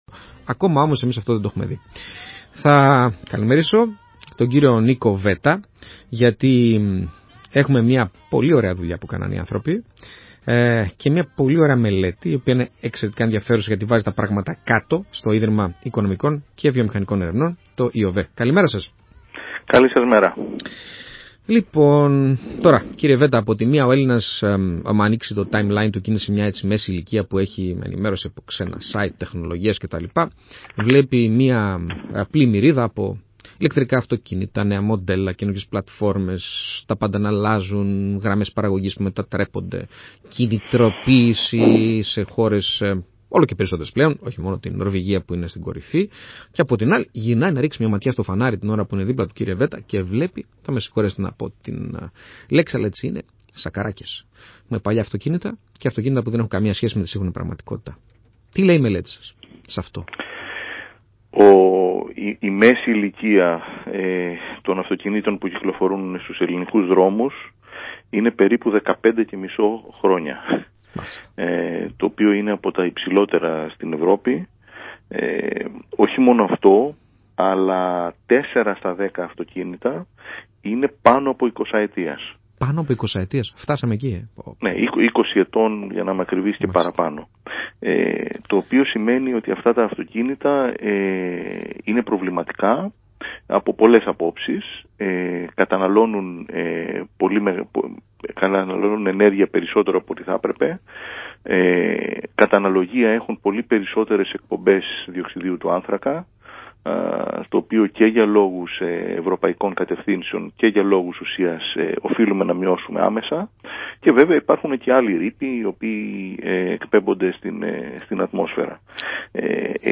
Συνέντευξη στο ραδιόφωνο ΑΠΕ ΜΠΕ